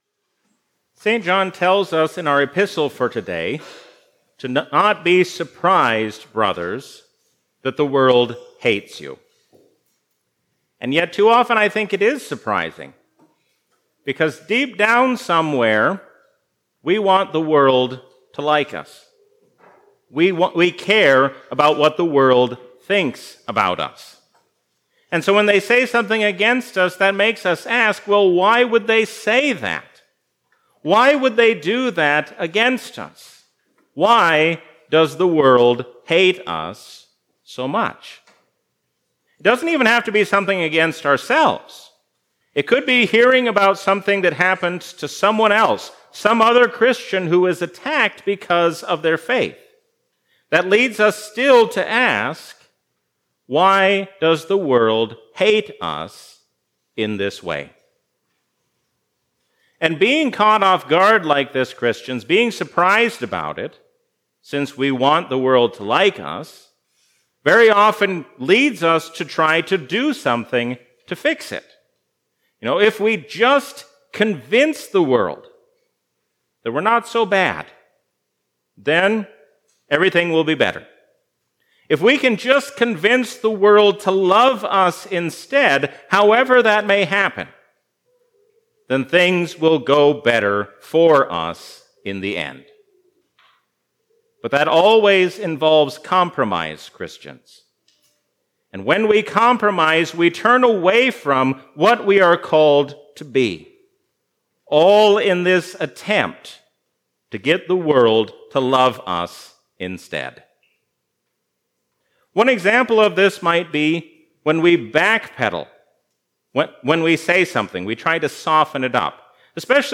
A sermon from the season "Trinity 2022." Stand firm against the hostile world, because Jesus reigns as the King of Kings and Lord of Lords forever.